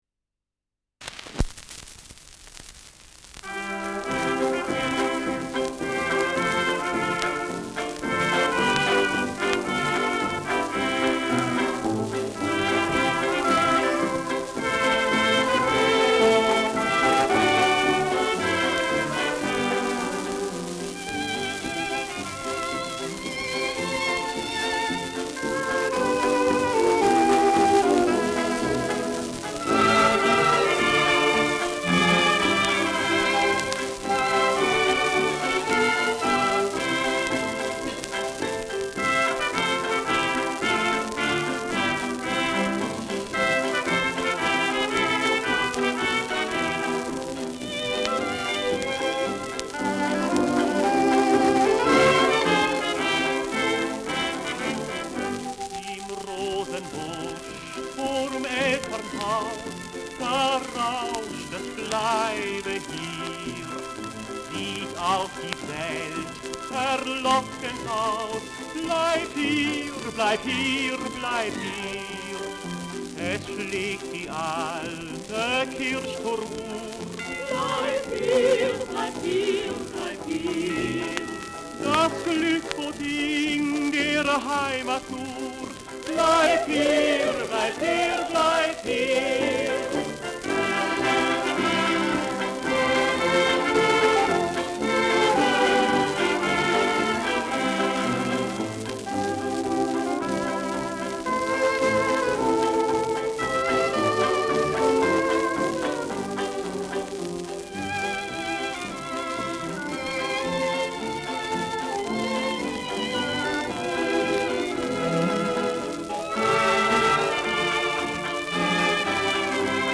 Schellackplatte
präsentes Rauschen